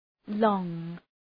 {lɔ:ŋ}